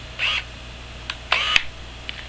9的对焦声音明显轻快一些。